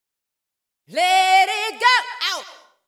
House / Voice / VOICEGRL074_HOUSE_125_A_SC2.wav